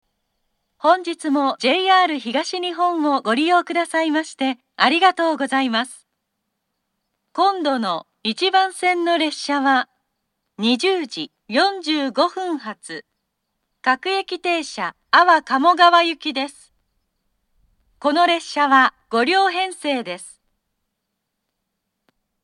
kazusa-okitsu-1bannsenn-jihatsu.mp3